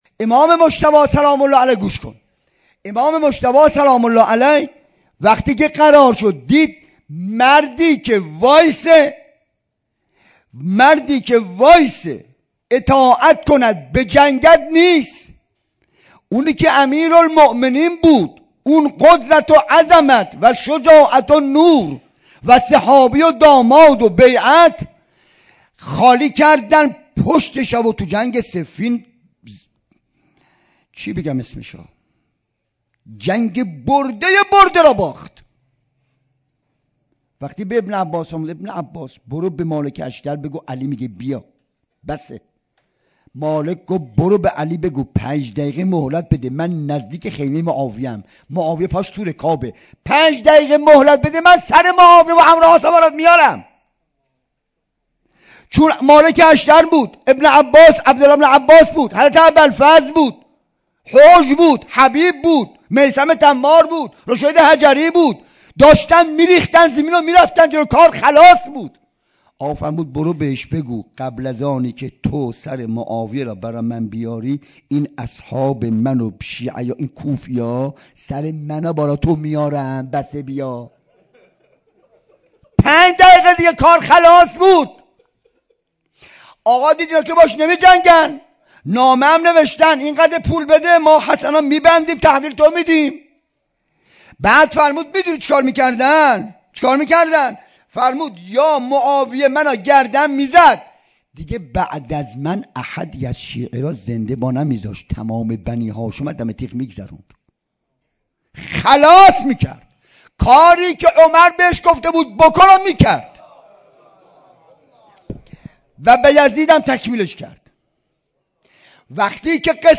سخنرانی و روضه خوانی شهادت امام حسن مجتبی (ع)